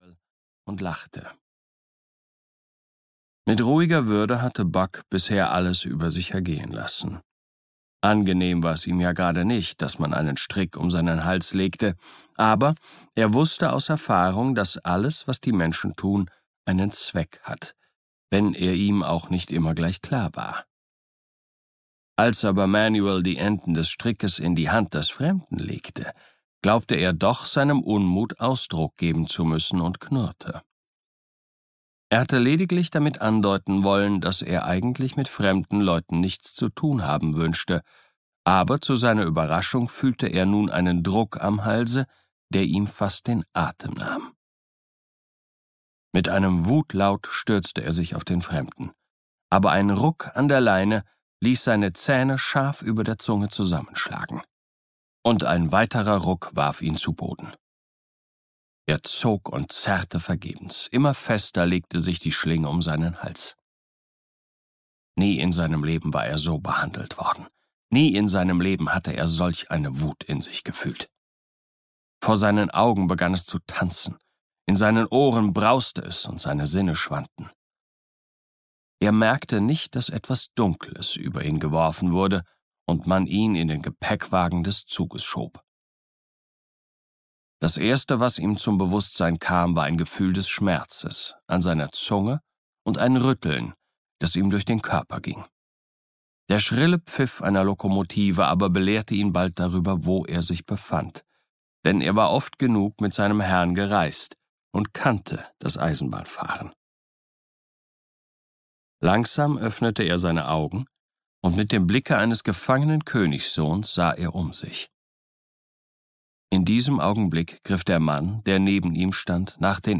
Audio knihaDer Ruf der Wildnis (DE)
Ukázka z knihy